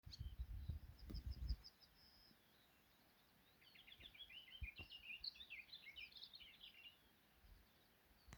Booted Warbler, Iduna caligata
Administratīvā teritorijaKrustpils novads
StatusSinging male in breeding season